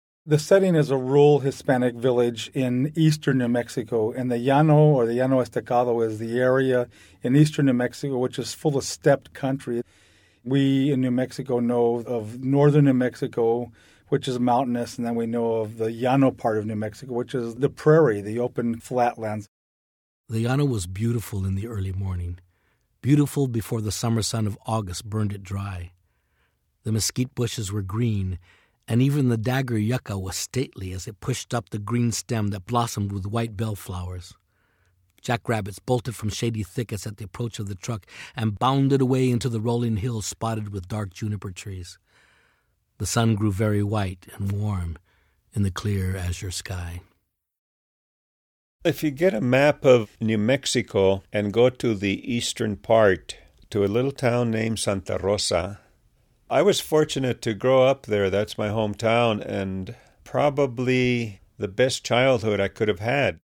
This moment also includes an excellent reading by actor Cheech Marin.